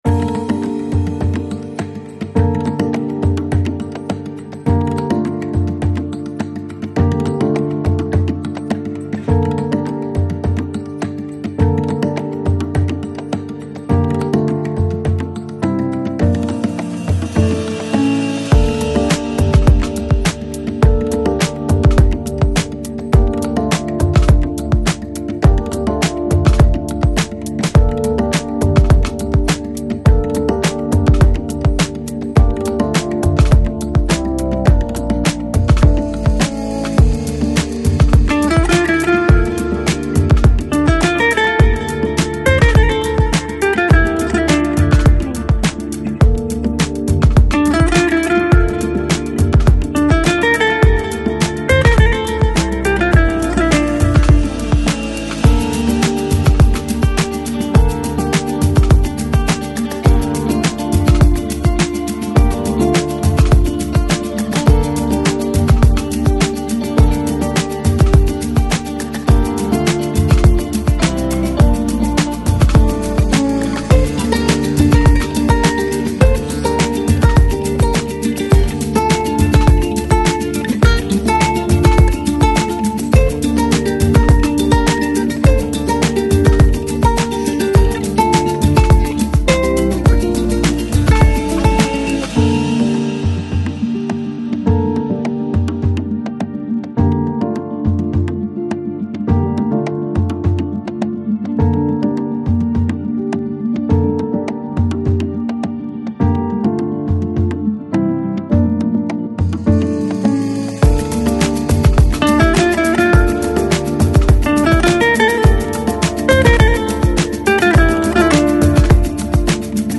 Жанр: Chill Out, Downtempo, Chill House, Deep House